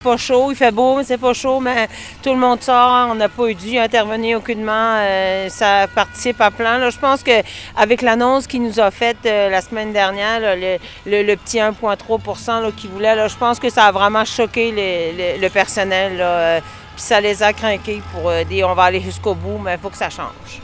En compagnie de syndiqués qui manifestaient bruyamment devant l’hôpital